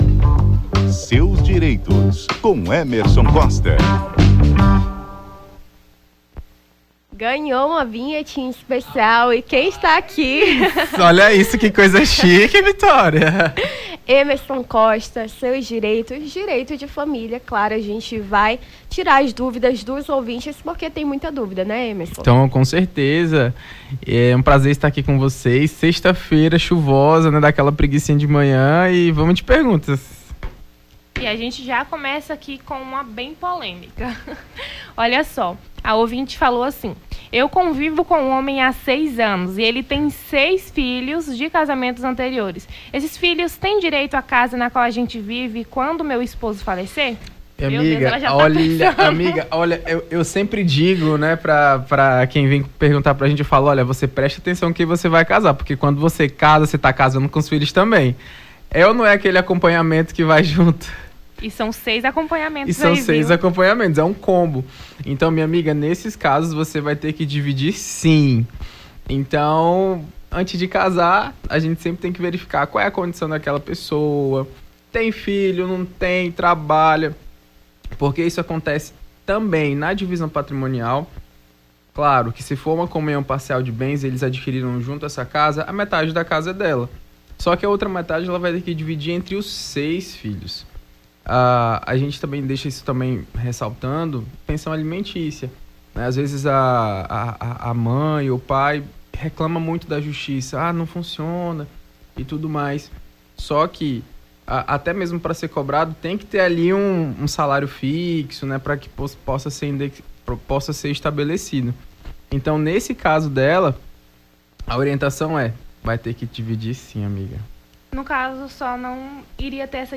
AO VIVO: Confira a Programação
Seus direitos: advogado esclarece dúvidas dos ouvintes sobre o direito da família